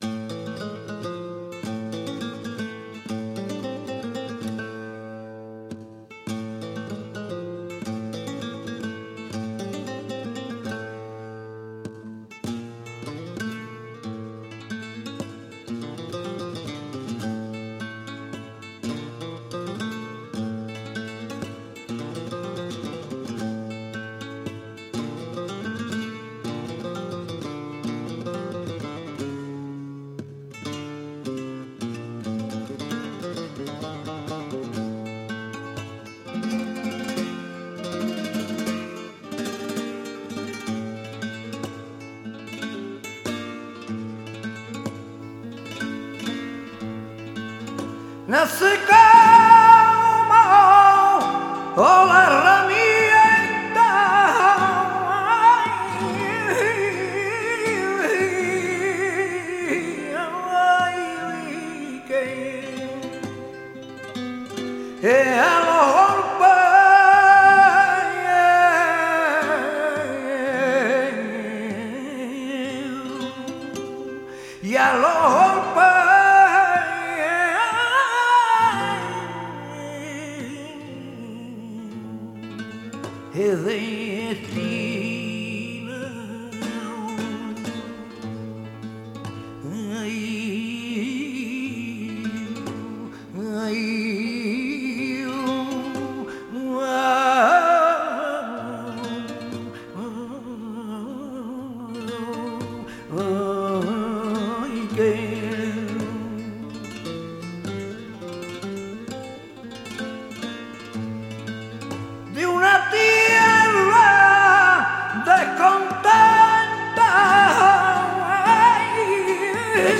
Polo et Soleá apolá) – guitare